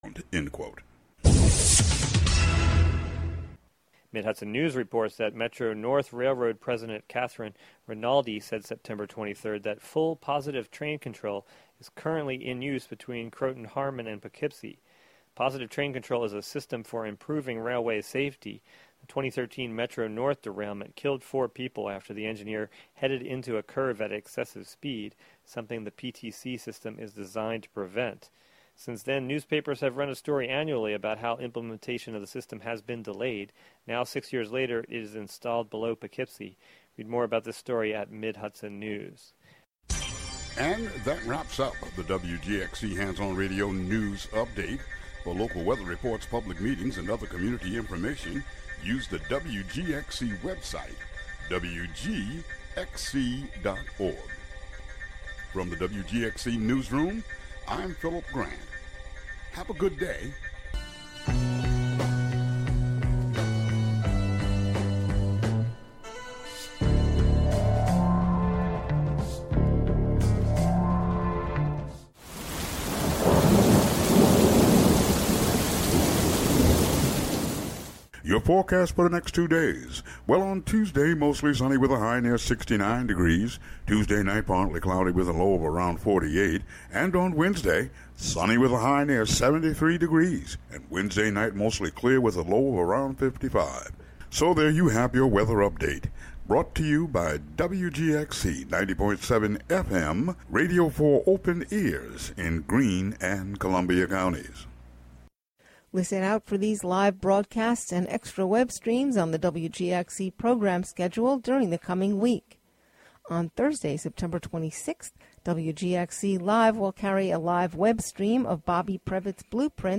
"All Together Now!" is a daily news show brought to you by WGXC-FM in Greene and Columbia counties.